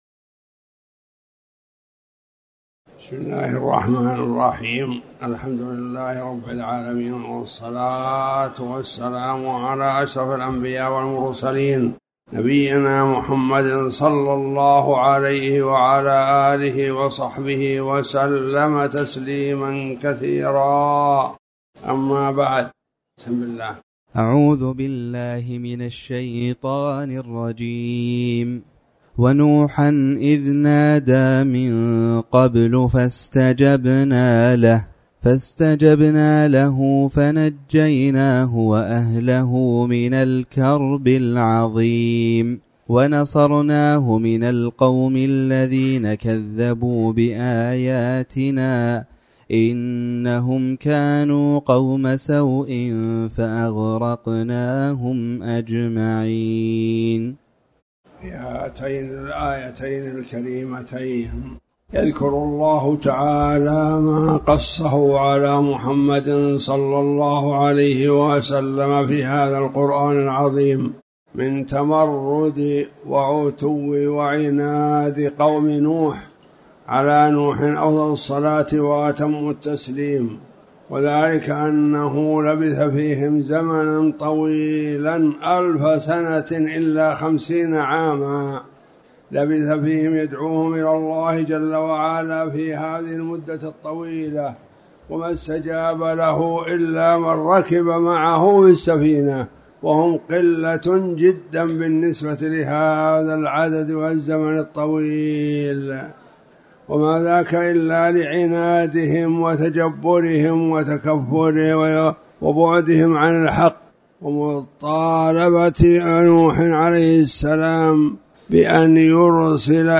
تاريخ النشر ١٩ رجب ١٤٤٠ هـ المكان: المسجد الحرام الشيخ